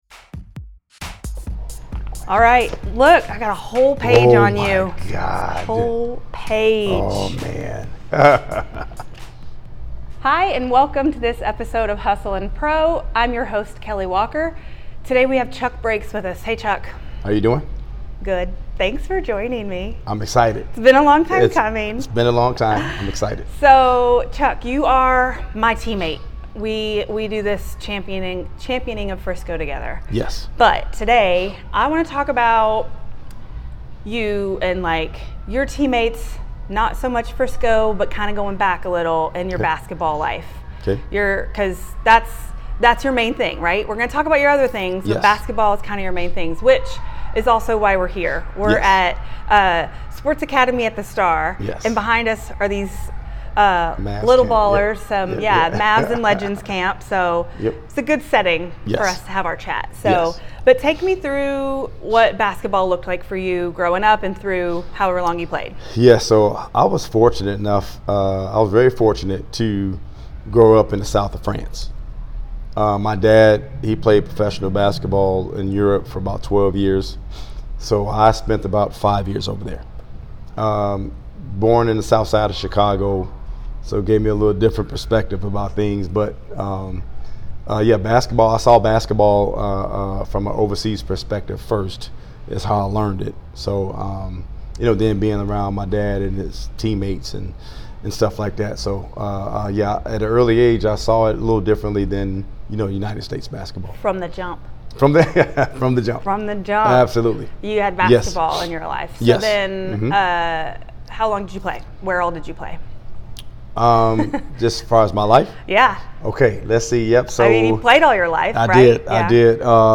Talking Court to Career
You can tell, we have fun.